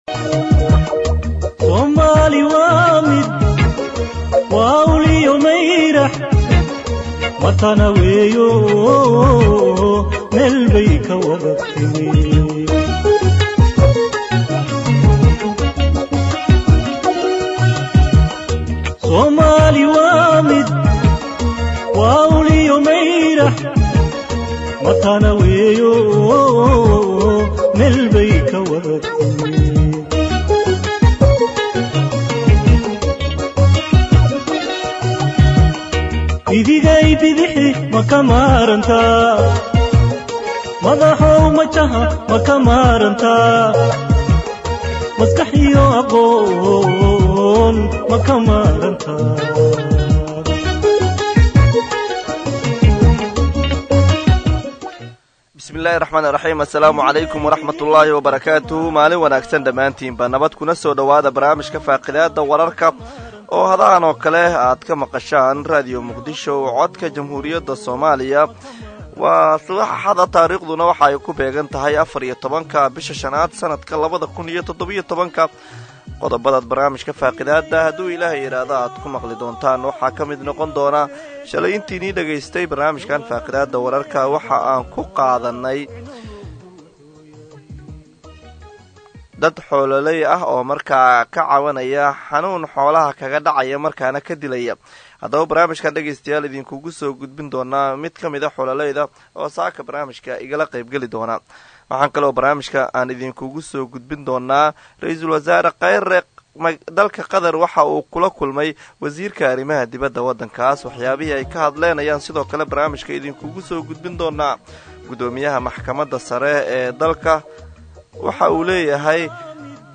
Akhristayaasheena sharafta leh waxaan halkaan idinkugu soo gudbineynaa Barnaamijka Faaqidaada oo ka baxa Radio Muqdisho subax waliba marka laga reebo subaxda Jimcaha, waxaana uu xambaarsanyahay macluumaad u badan wareysiyo iyo falaqeyn xagga wararka ka baxa Idaacadda, kuwooda ugu xiisaha badan maalintaas.